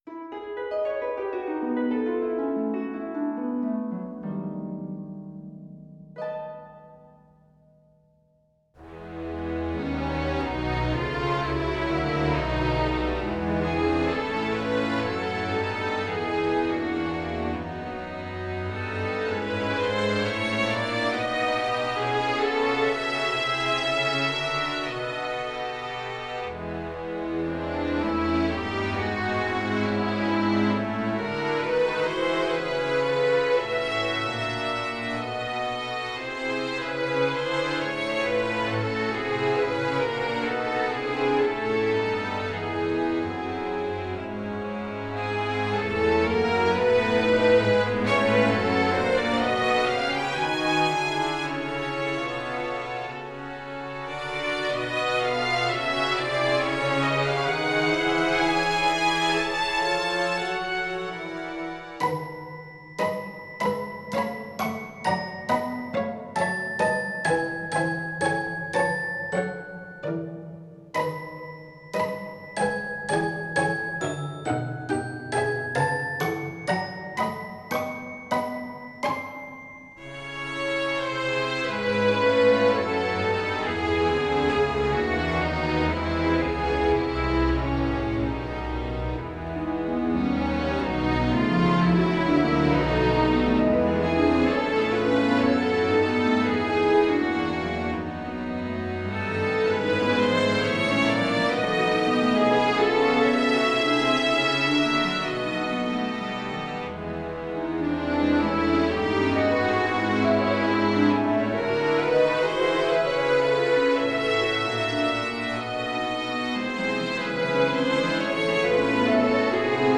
第2曲は、ストリングスがメインの曲です。